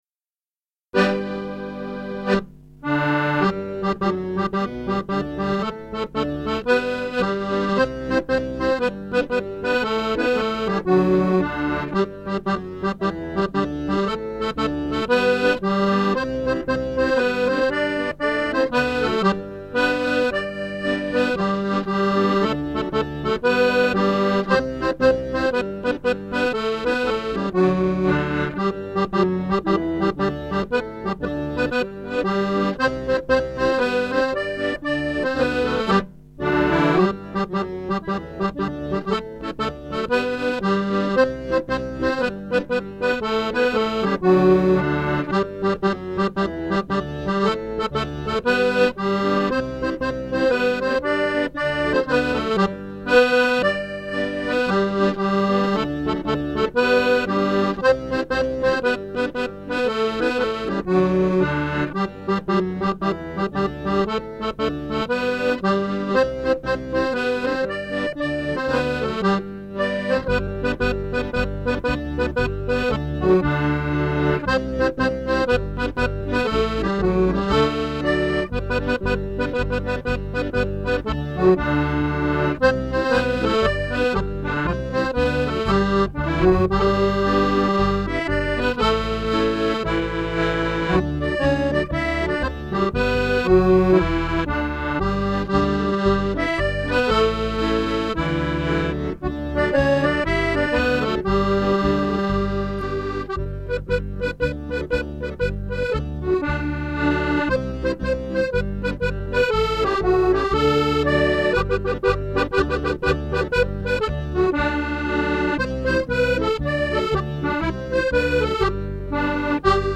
Music - 32 bar jigs